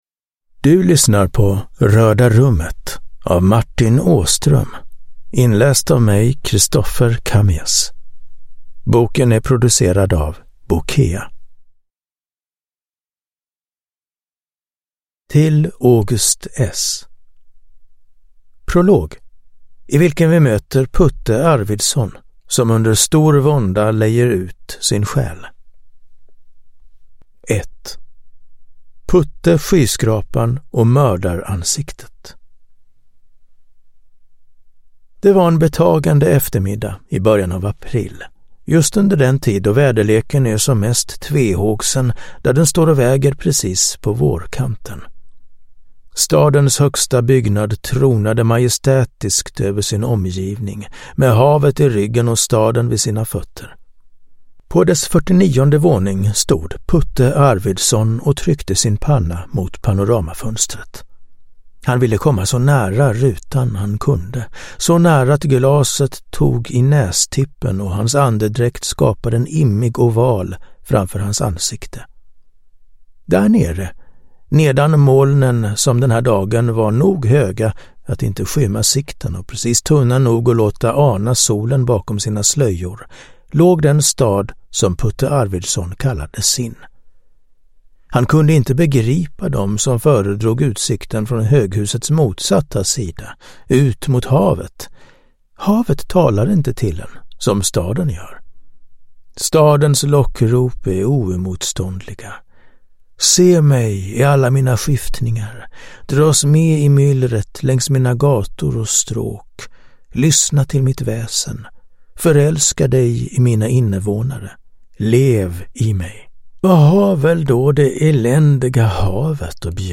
Röda Rummet (ljudbok